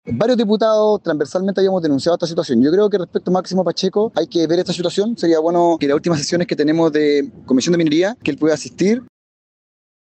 En un tono más conciliador, el diputado independiente del mismo comité, Sebastián Videla, señaló que algunos parlamentarios ya habían advertido sobre este eventual ocultamiento y, al mismo tiempo, instó al presidente del directorio a asistir a las próximas sesiones de la comisión de Minería para entregar explicaciones.